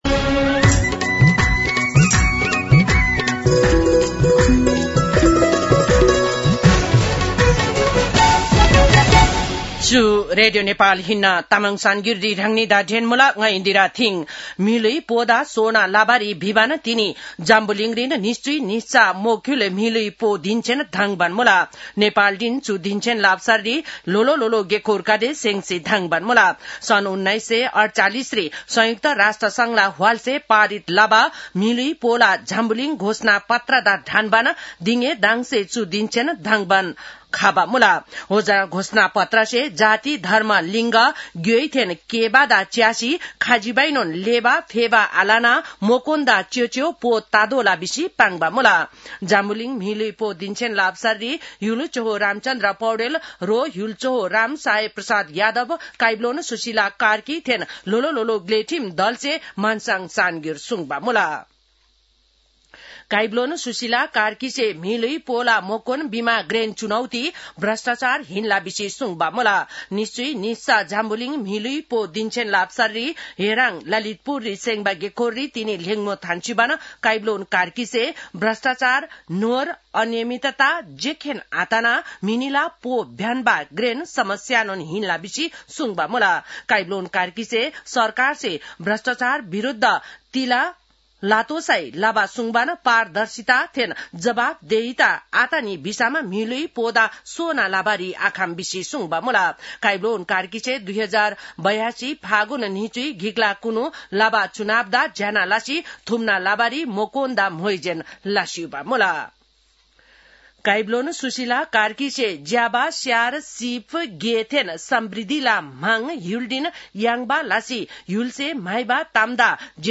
तामाङ भाषाको समाचार : २४ मंसिर , २०८२